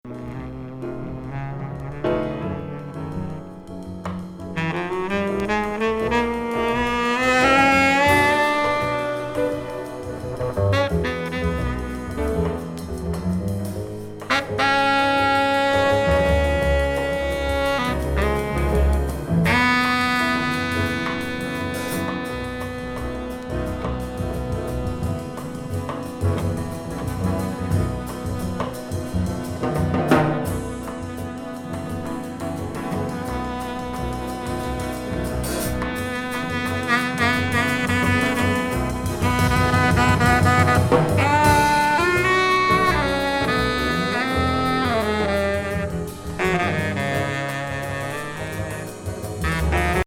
メロディックなピアノに、クールに打たれるラテン風のドラム、エモーショナルなブロウが